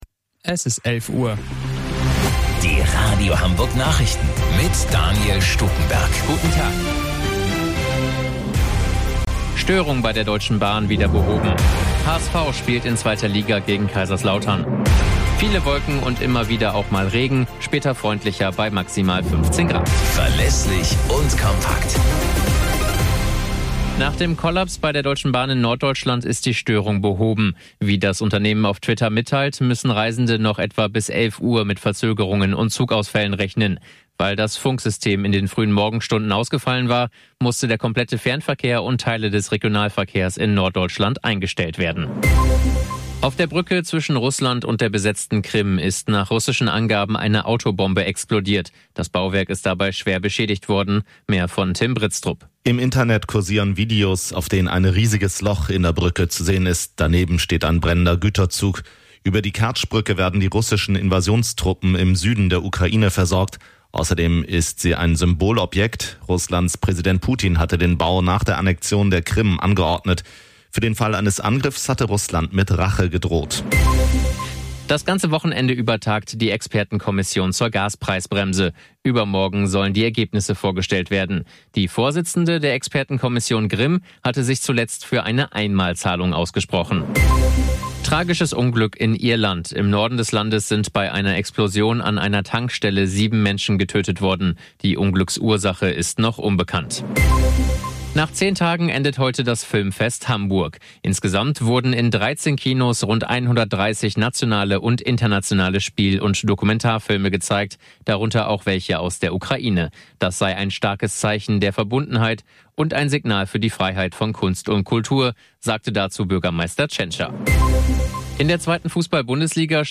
Radio Hamburg Nachrichten vom 10.06.2022 um 12 Uhr - 10.06.2022